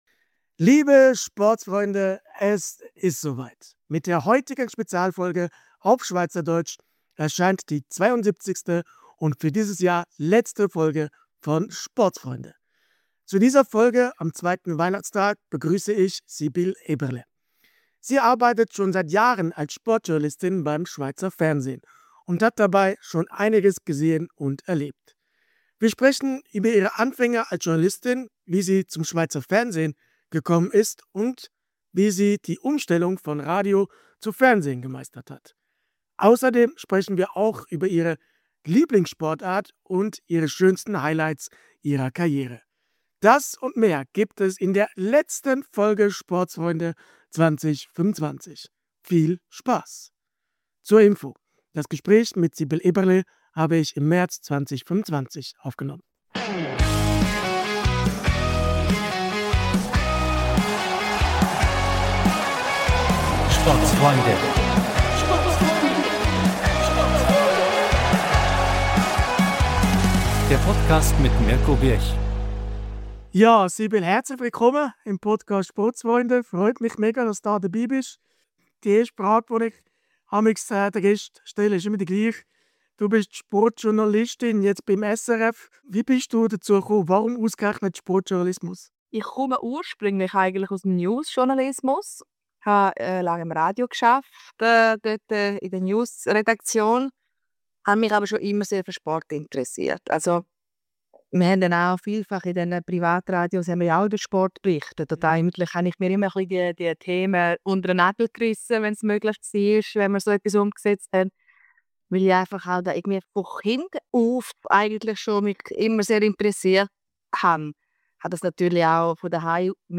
Mit der heutigen SPEZIALFOLGE auf SCHWEIZERDEUTSCH erscheint die 72. und somit letzte Folge von SPORTSFREUNDE im Jahr 2025!